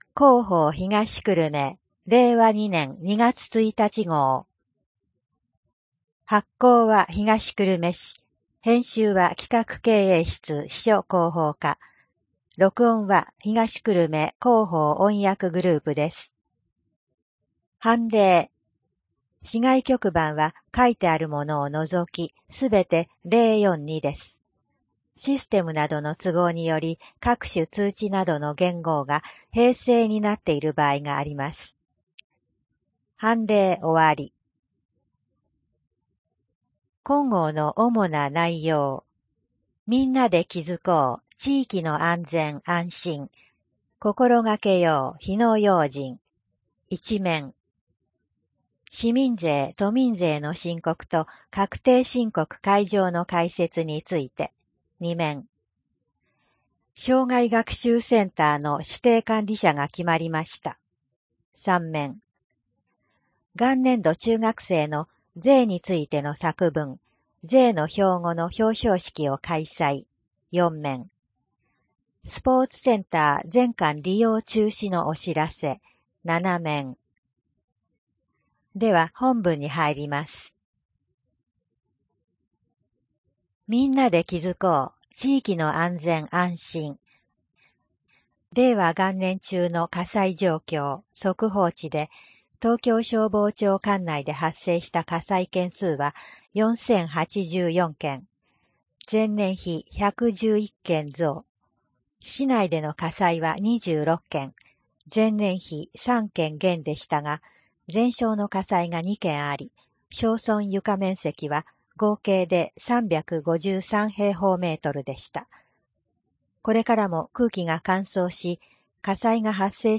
声の広報（令和2年2月1日号）